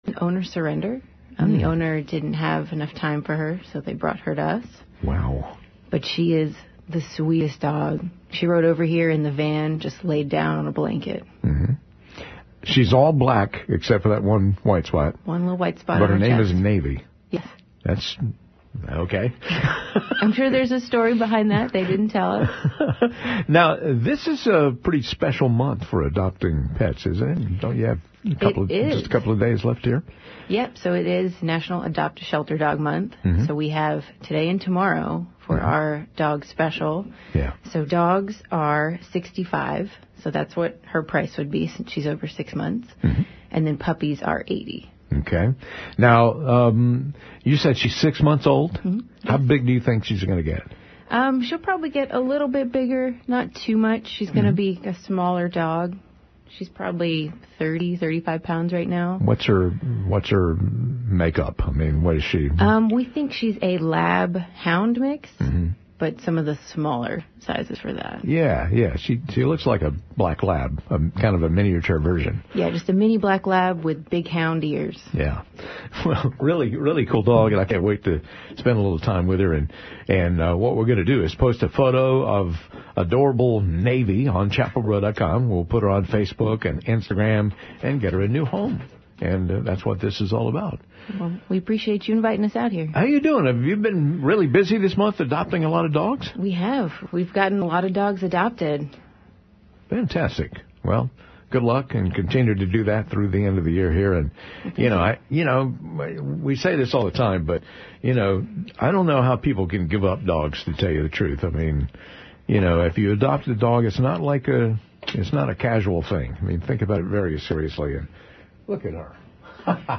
Navy was super excited to see everyone in the studio today and made sure to give lots of kisses! She is a black Labrador retriever who the staff thinks is about six months old.